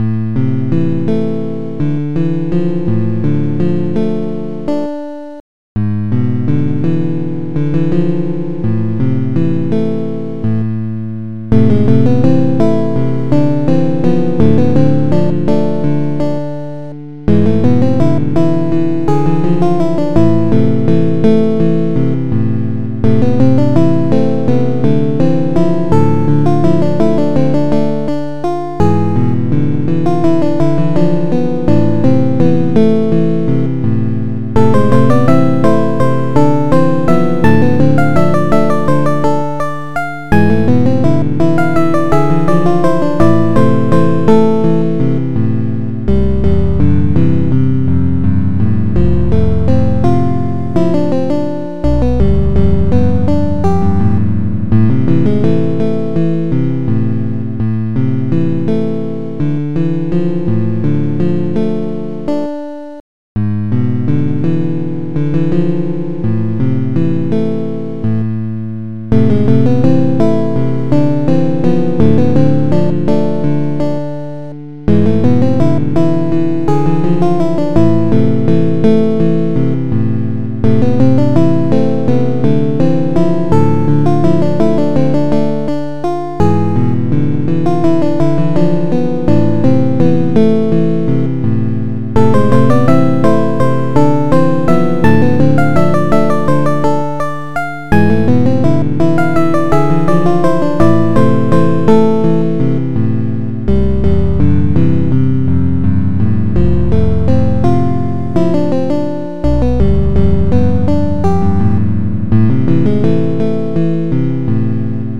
Tracker DSIK DSM-format
Acoustic Guitar2